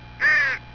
Crow3
CROW3.wav